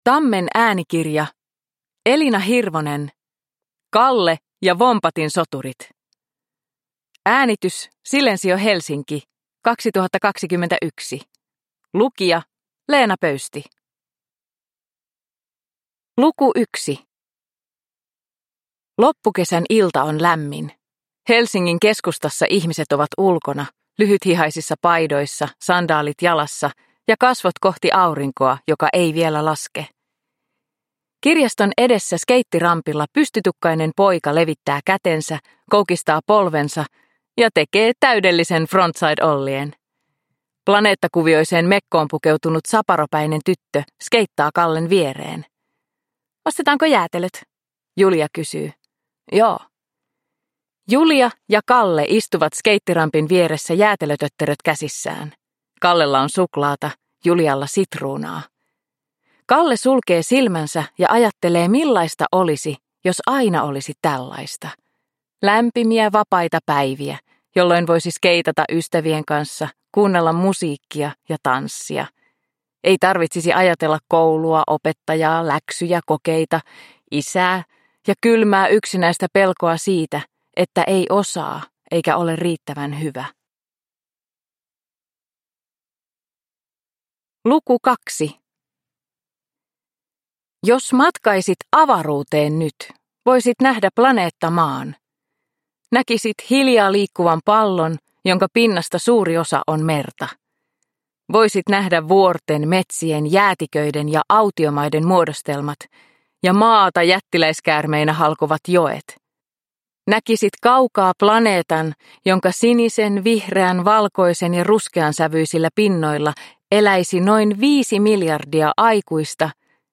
Kalle ja Vompatin Soturit – Ljudbok – Laddas ner